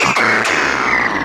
infinitefusion-e18/Audio/SE/Cries/PASSIMIAN.ogg at a50151c4af7b086115dea36392b4bdbb65a07231